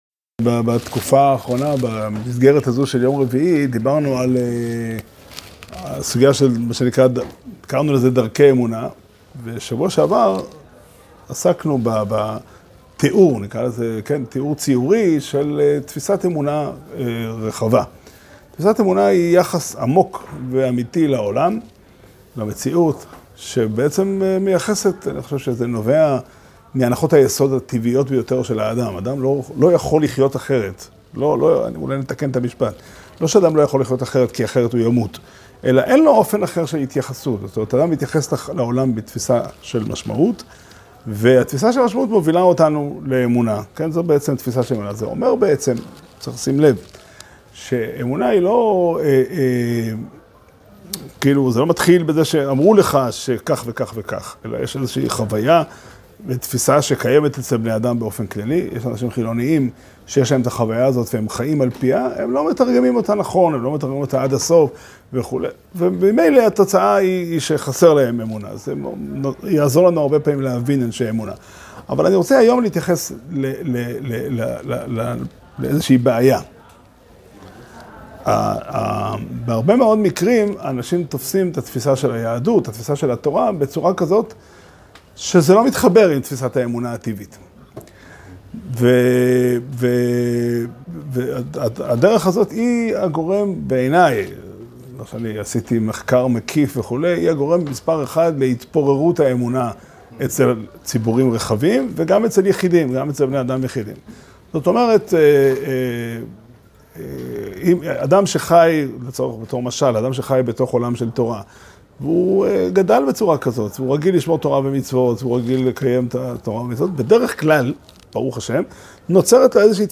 שיעור שנמסר בבית המדרש פתחי עולם בתאריך ג' אב תשפ"ד